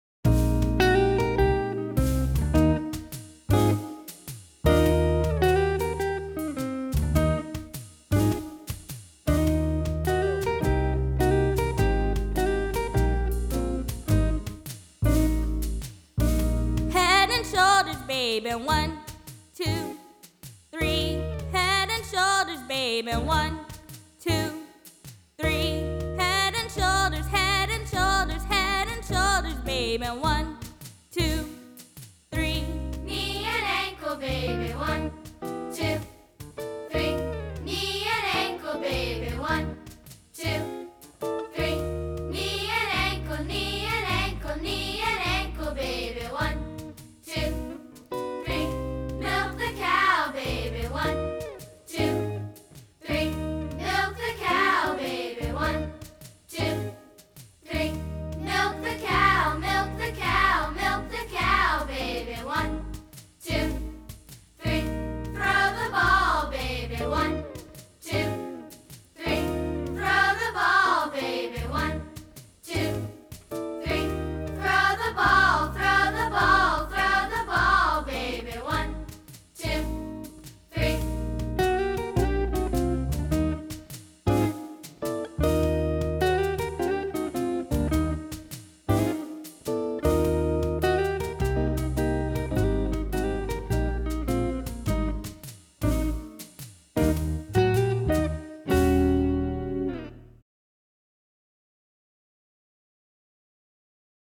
Here is the background music for “Head and Shoulders Baby”, which we learned in music class.